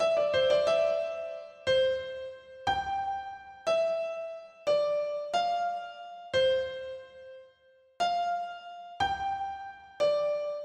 标签： 90 bpm Ambient Loops Piano Loops 5.38 MB wav Key : A
声道立体声